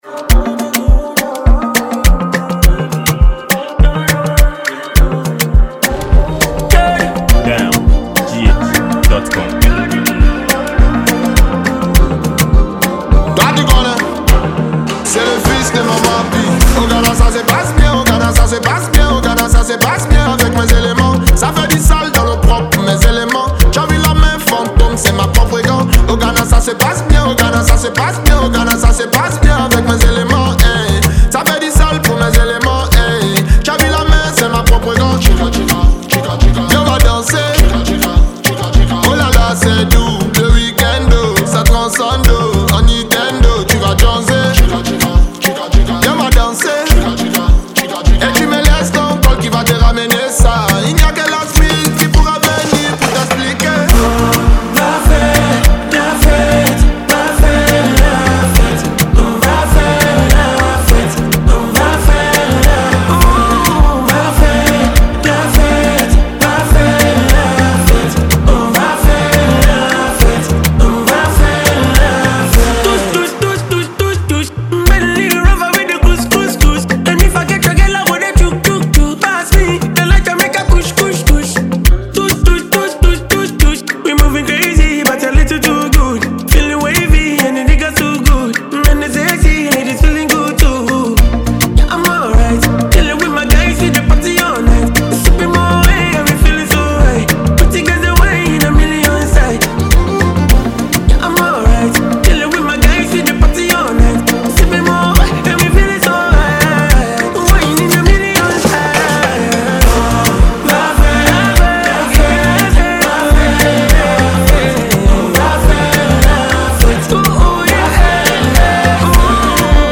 Ghanaian singer, songwriter and musician
banger
Francophone singer